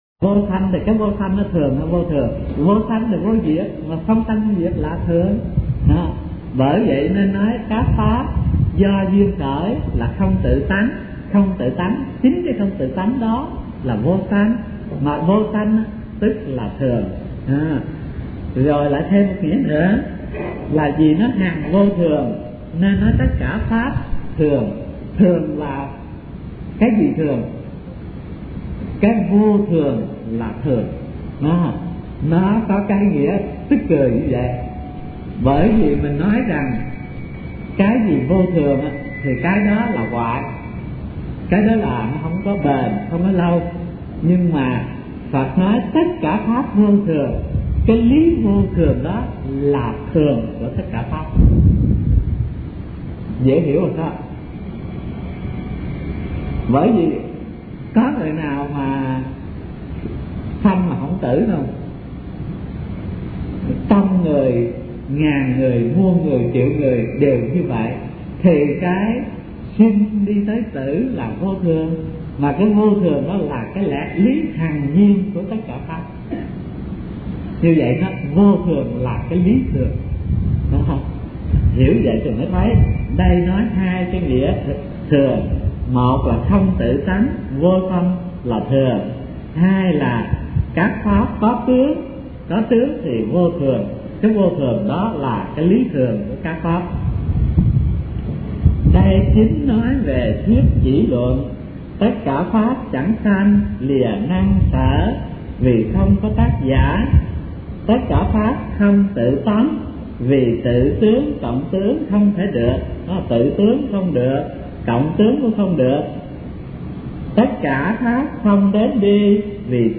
Kinh Giảng Kinh Lăng Già - Thích Thanh Từ